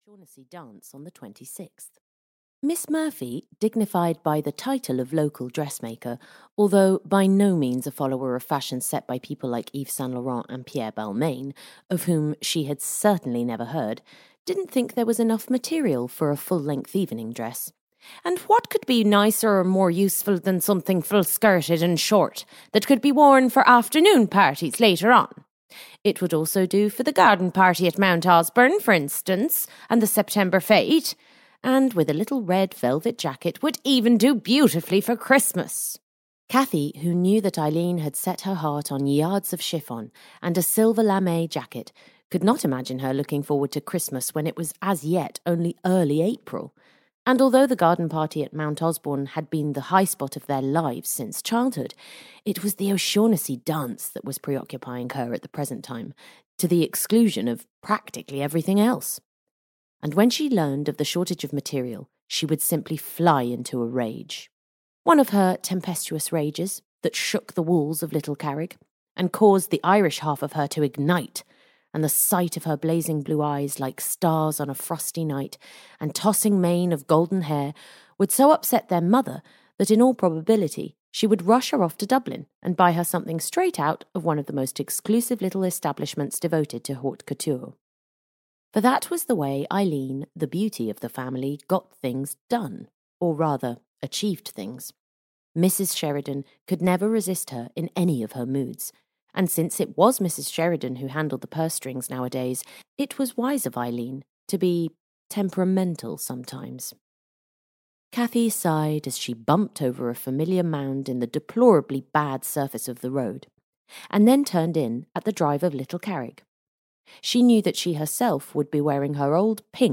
The Wings of the Morning (EN) audiokniha
Ukázka z knihy